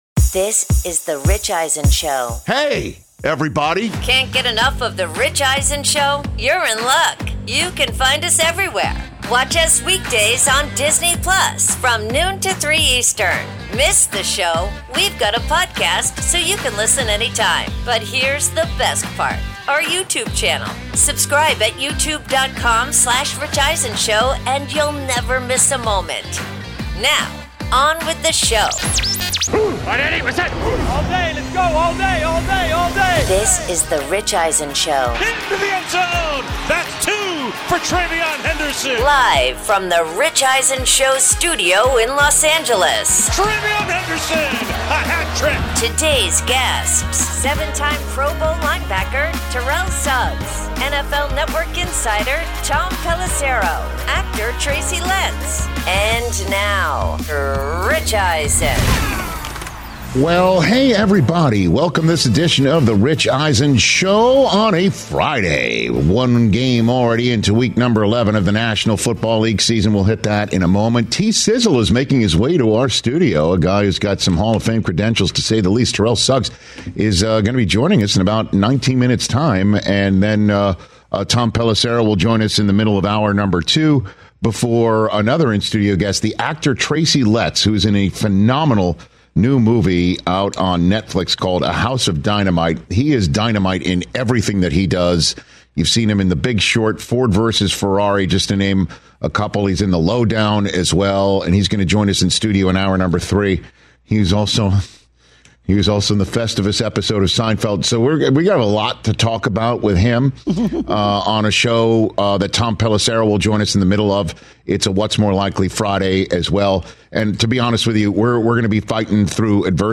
Hour 1: Patriots and Jets are Going Opposite Directions, plus Terrell Suggs In-Studio